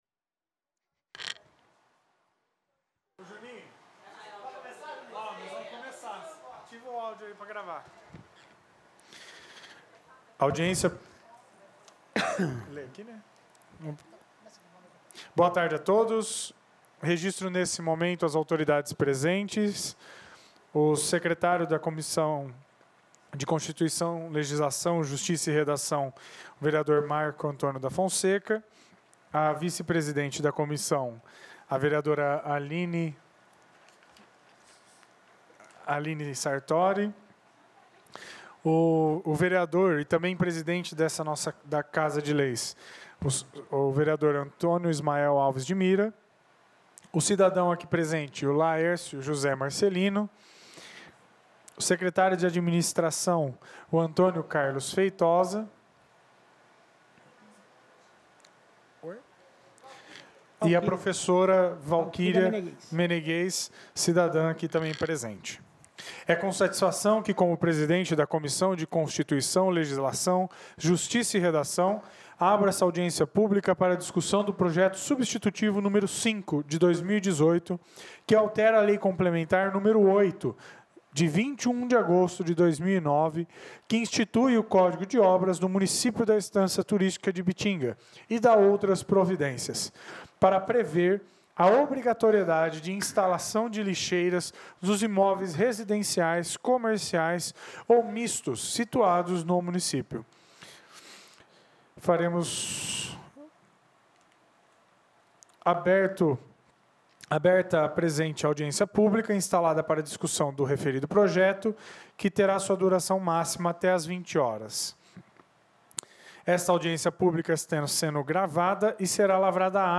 Audiências Públicas